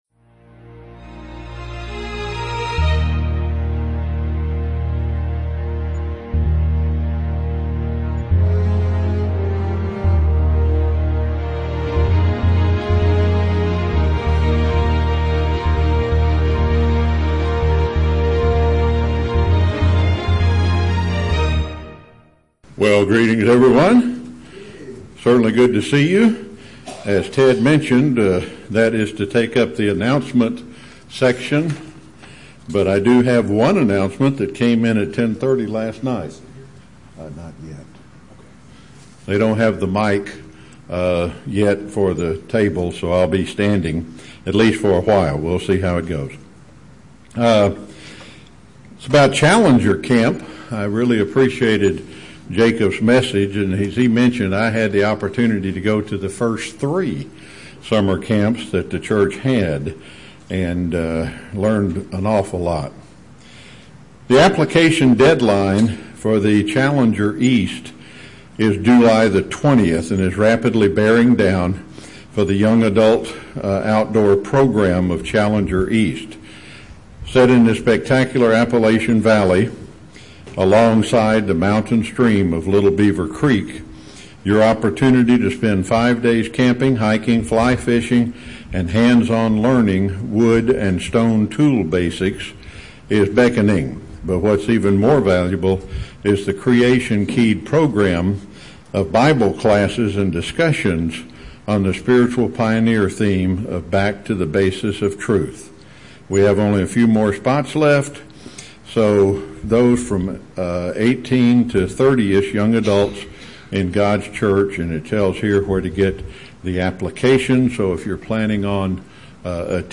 Sermon: God’s View of Money (New Living Translation)